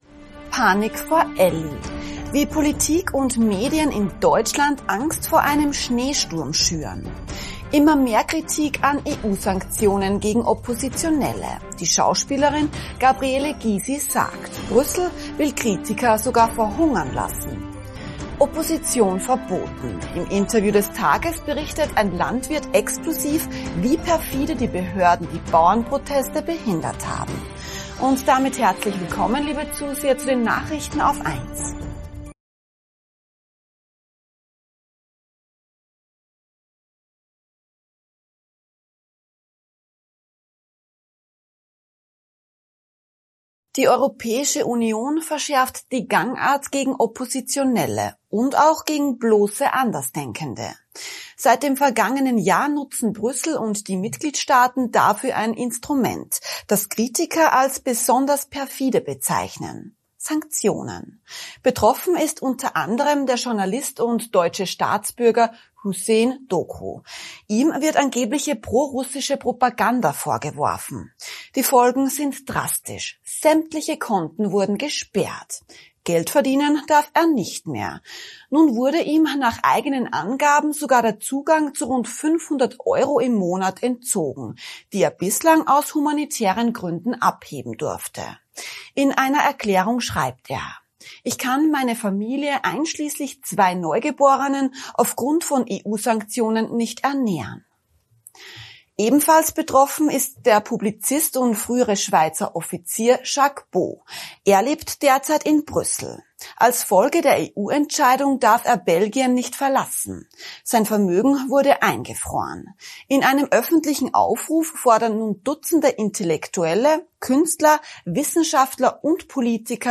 Im Interview des Tages berichtet ein Landwirt exklusiv, wie perfide die Behörden die Bauern-Proteste behindert haben.